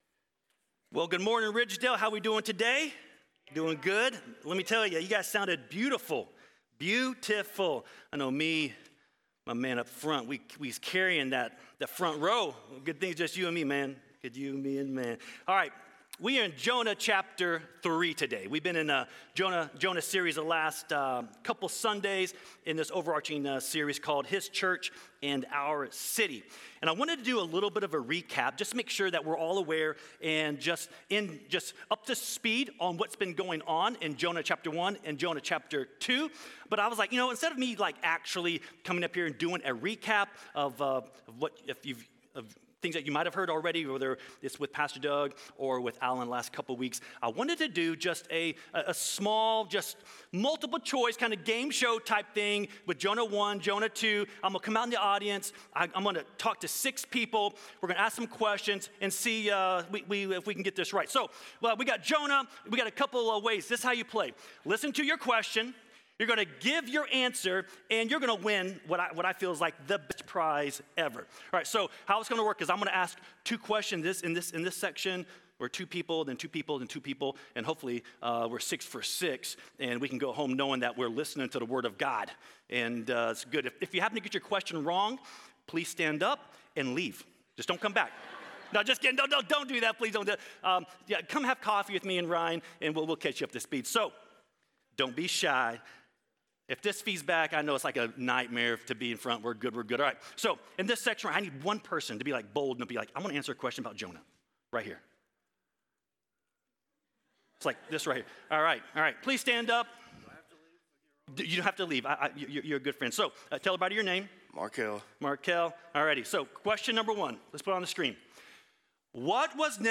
Home › Resources › Sermons › Jonah 3 https